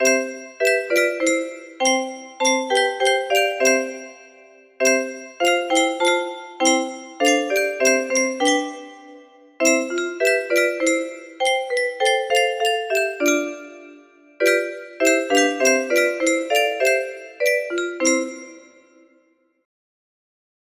Abide with Me music box melody
Imported from abide_with_meorg.mid and transposed to key of C